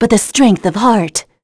Scarlet-vox-get_02.wav